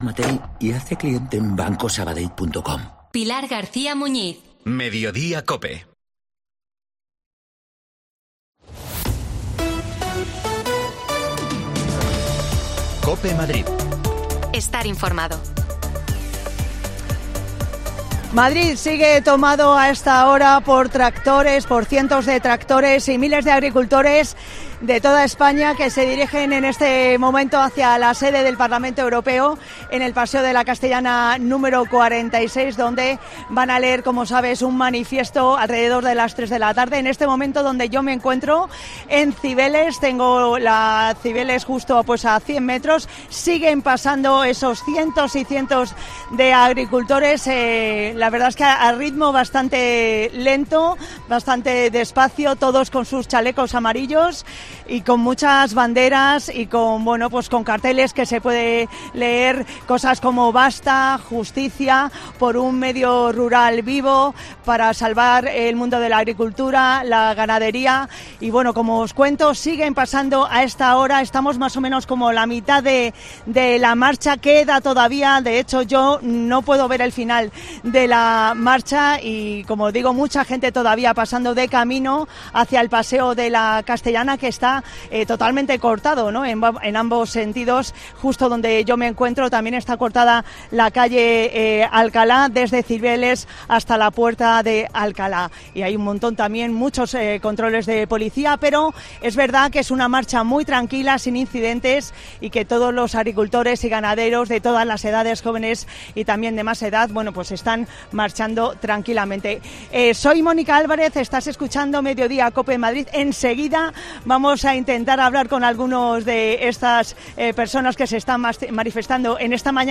AUDIO: Nueva protesta de agricultores y ganaderos en Madrid. Te lo contamos desde la calle